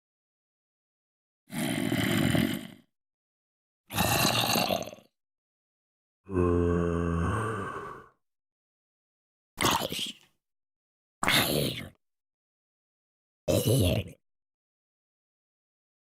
Множество звуков зомби
звуки-зомби-из-майнкрафта-_-zombie-sounds-from-minecraft-256-kbps.mp3